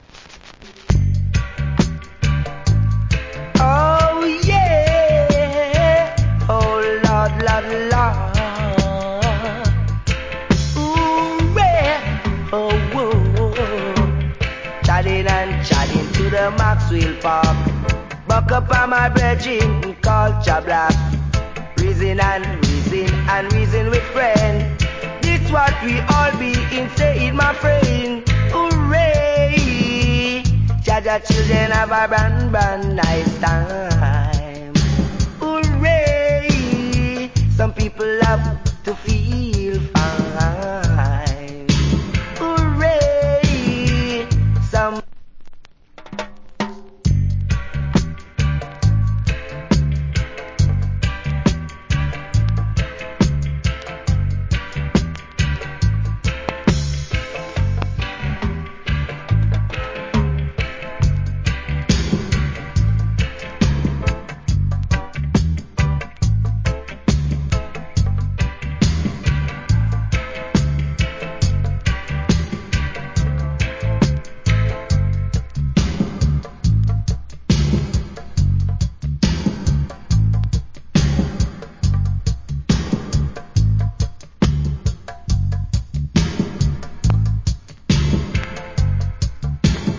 Roots.